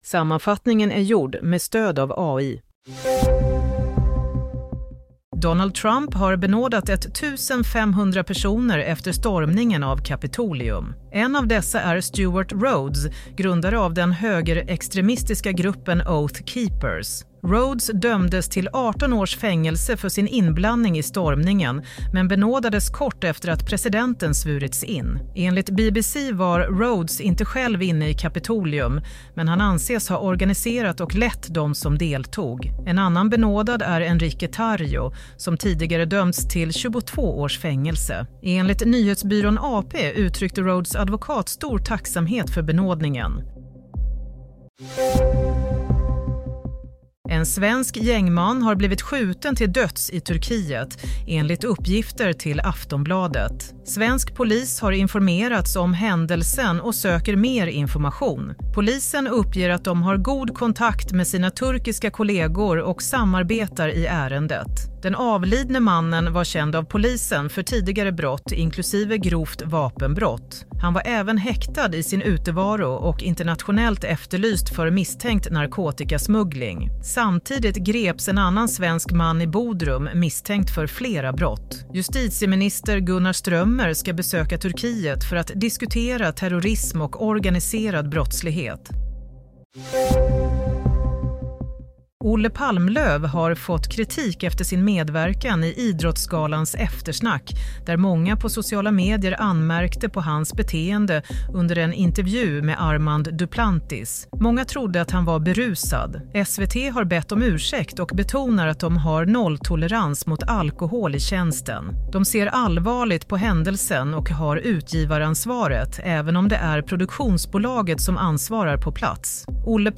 Nyhetssammanfattning - 21 januari 16:00